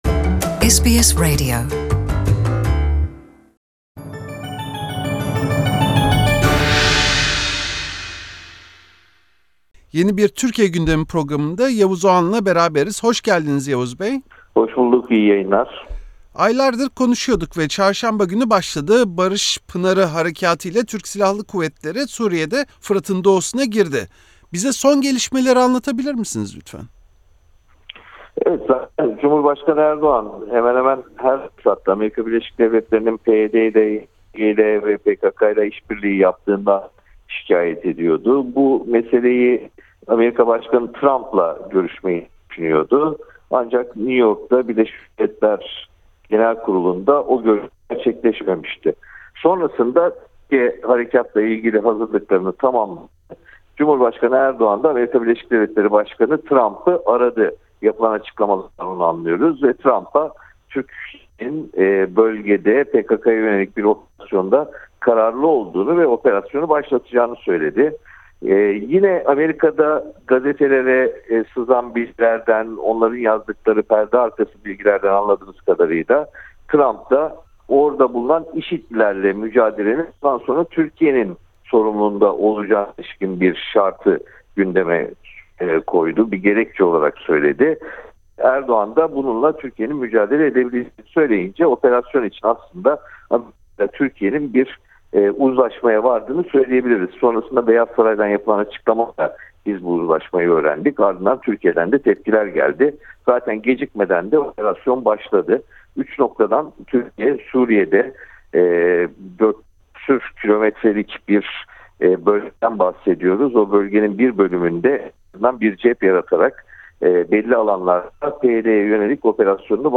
Gazeteci Yavuz Oğhan, SBS Türkçe’ye verdiği röportajda, Türkiye’nin Suriye’deki YPG/PYD güçlerine yönelik askeri operasyonunu değerlendirdi.